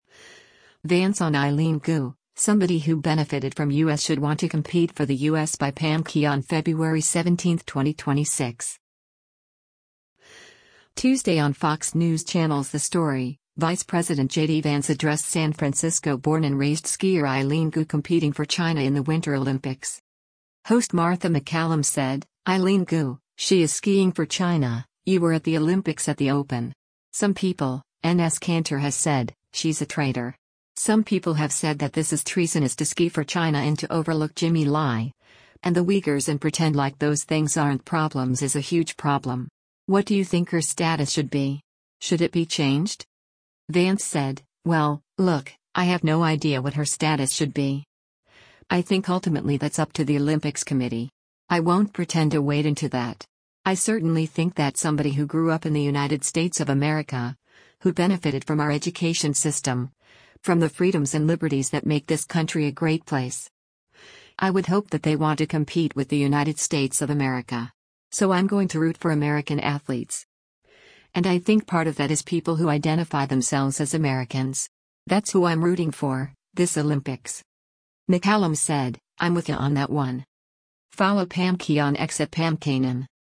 Tuesday on Fox News Channel’s “The Story,” Vice President JD Vance addressed San Francisco-born-and-raised skier Eileen Gu competing for China in the Winter Olympics.